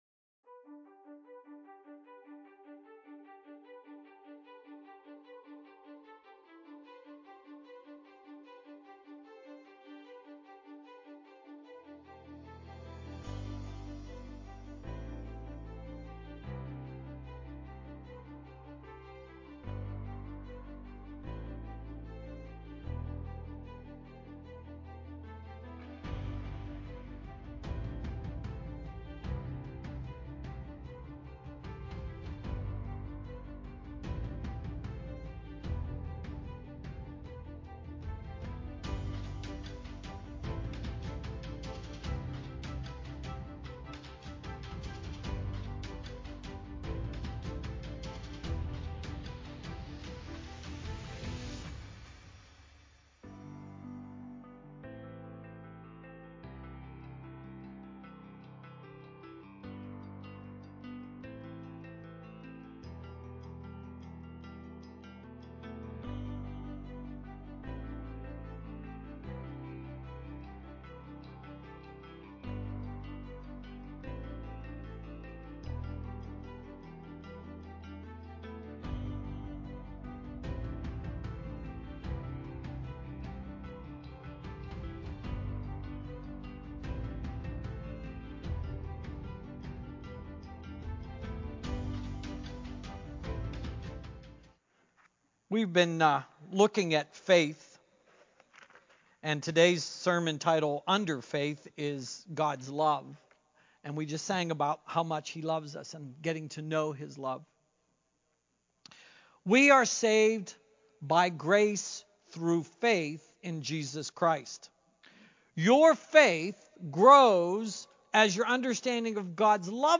Faith -God’s Love Sermon
Faith-Gods-Love-Sermon-Audio-CD.mp3